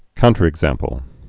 (kountər-ĭg-zămpəl)